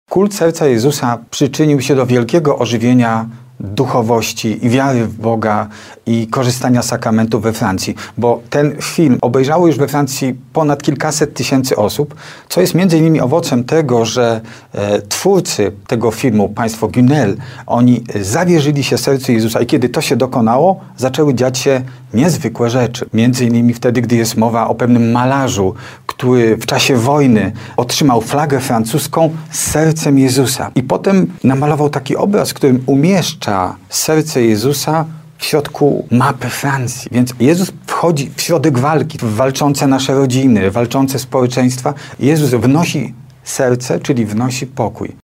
mówił kaznodzieja, publicysta i wykładowca homiletyki